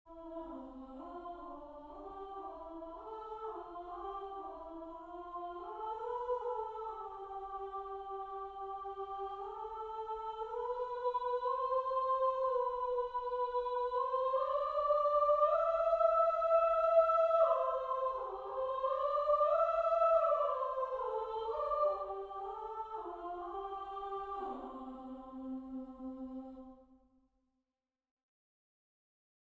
3vv Voicing: 3 equal voices Genre
Language: English Instruments: A cappella
First published: 2025 Description: A three voice round.